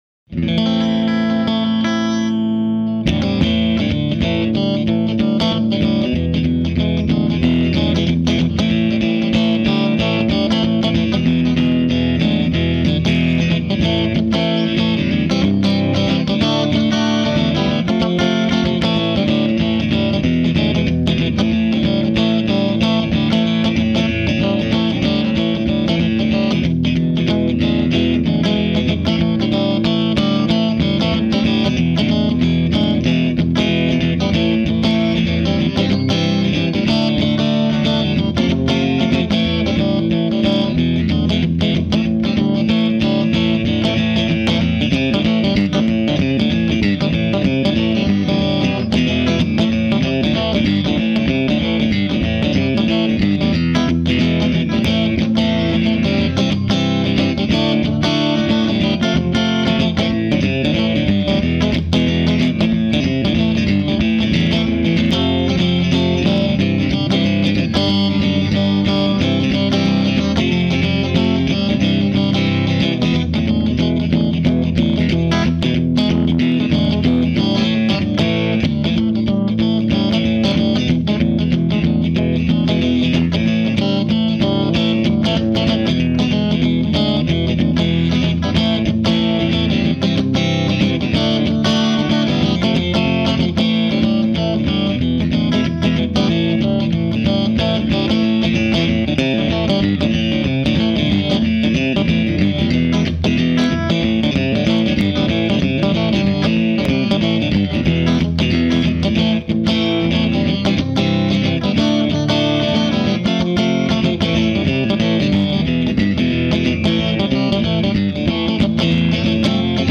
Well, I ended up with just a few minutes to toss up a mic and play a bit. This is just moving through the 5 positions on the Sunburst Strat from bridge to neck and a little shuffle in Amin.
Normal Vol at 7 tone set all at 6 except for Pres at about 2.
Just got a chance to listen to the two clips- great tones and very nice pickin' too!
amin_shuffle.mp3